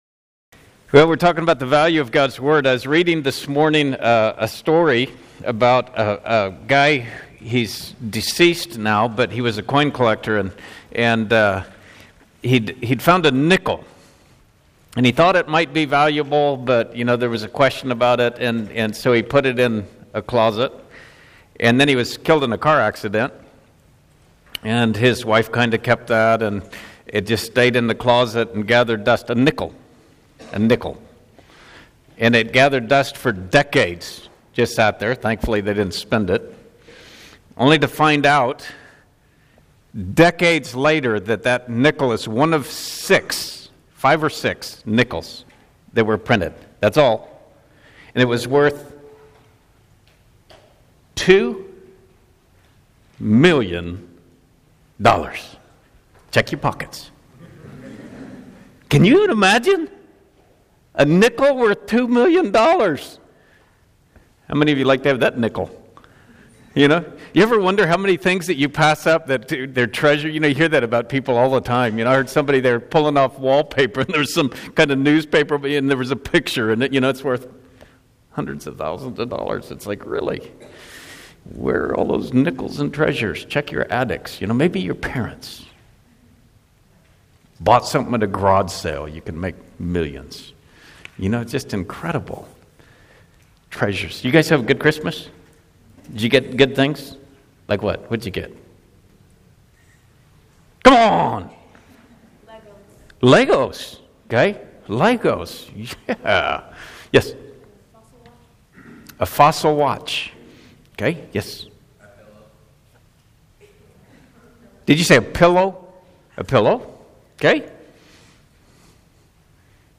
High School HS Winter Camp - 2016 Audio ◀ Prev Series List Next ▶ Previous 1.